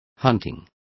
Complete with pronunciation of the translation of hunting.